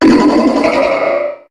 Cri de Méga-Charmina dans Pokémon HOME.
Cri_0308_Méga_HOME.ogg